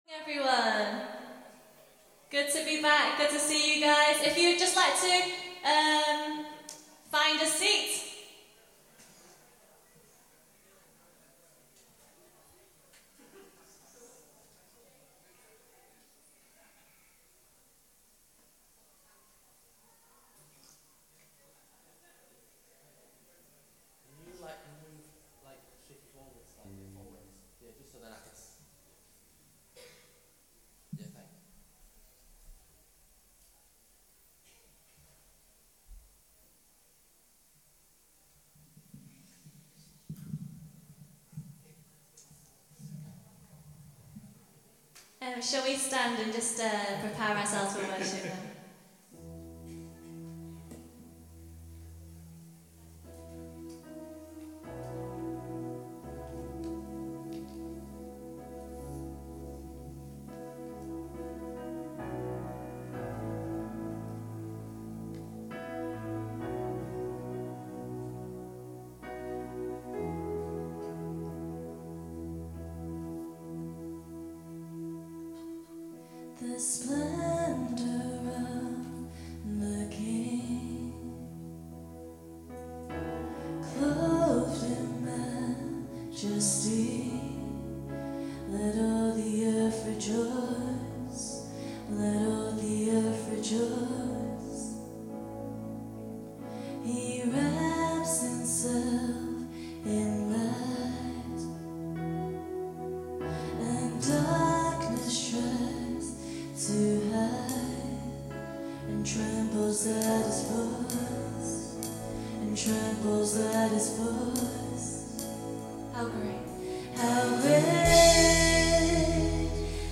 Worship January 15, 2015 – Birmingham Chinese Evangelical Church
Guitar
Bass